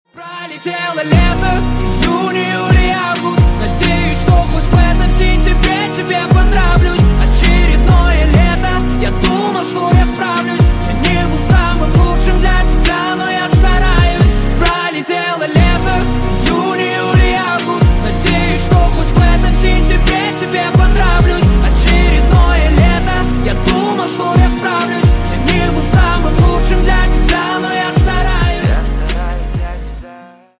Русские Рингтоны » # Поп Рингтоны